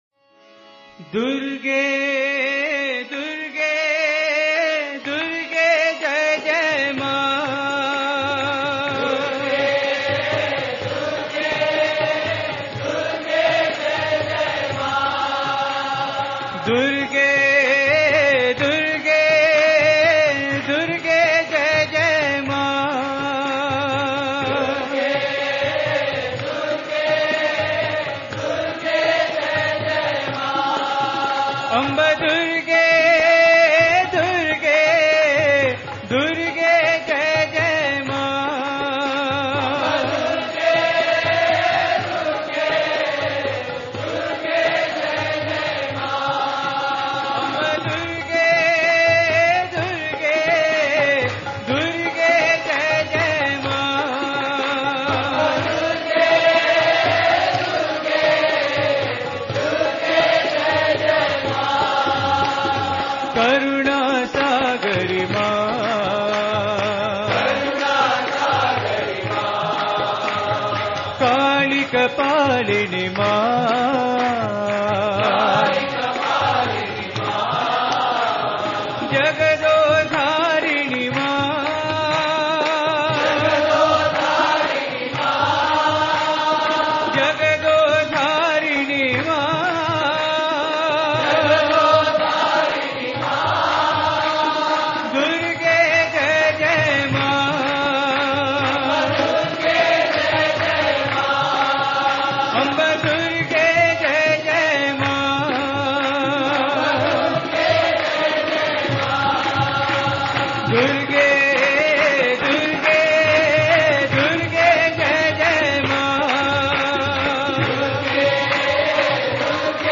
Repeat the whole thing at a faster tempo.
Sai-Bhajan-Durge-Durge-Durge-Jai-Jai-Ma.mp3